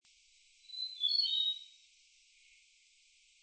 4-5白尾鴝扇平2.mp3
白尾鴝 Cinclidium leucurum montium
高雄市 六龜區 扇平
錄音環境 森林
雄鳥歌聲
Sennheiser 型號 ME 67